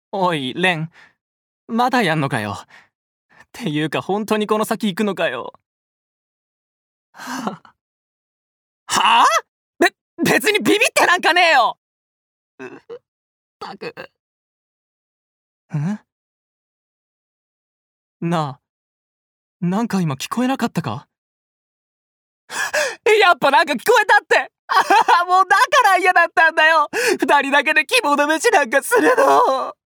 VOICE SAMPLE
セリフ4